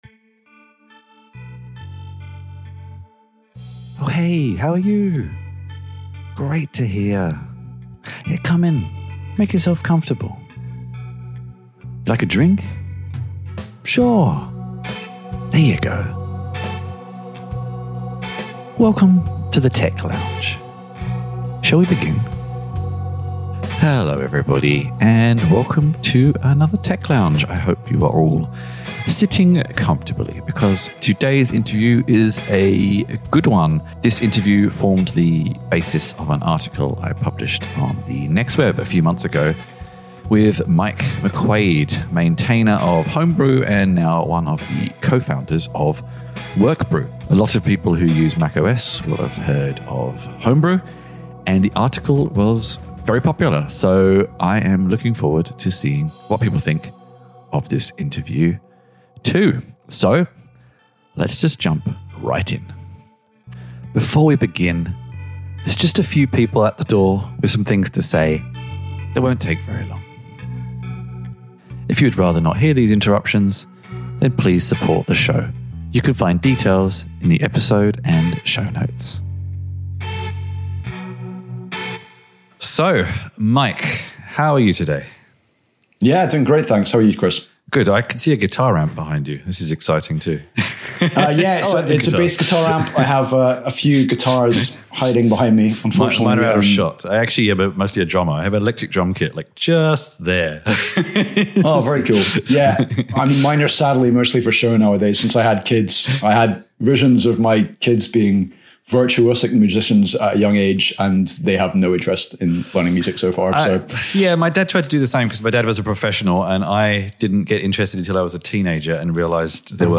This interview formed the basis of my article on The Next Web in July